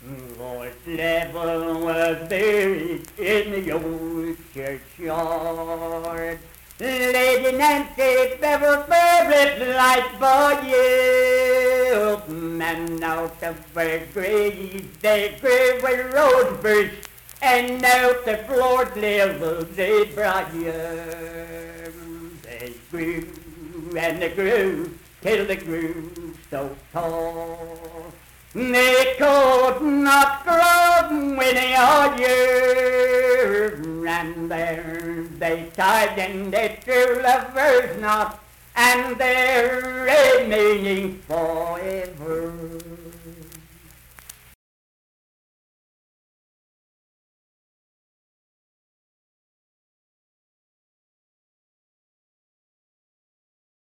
Unaccompanied vocal music performance
Verse-refrain 2(4).
Voice (sung)